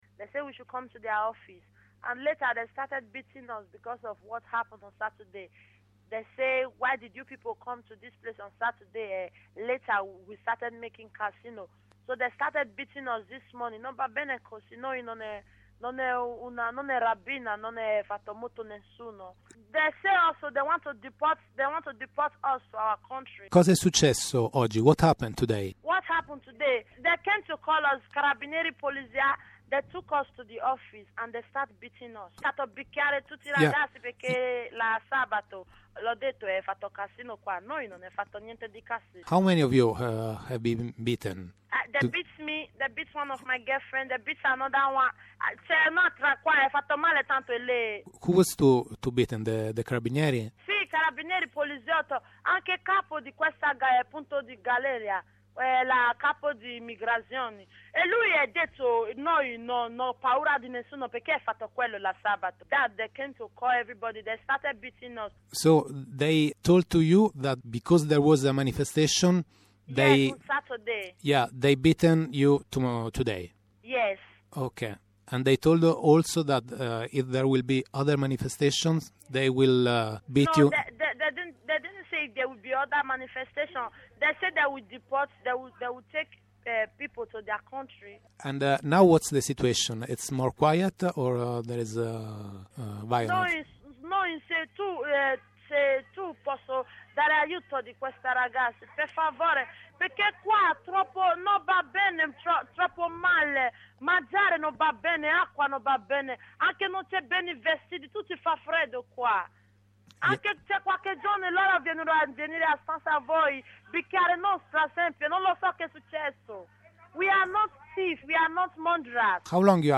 Una donna che si trova rinchiusa nel Cie di Ponte Galeria da cinque mesi telefona a Radio OndaRossa per raccontare - in un misto di italiano e inglese - il pestaggio subito stamattina.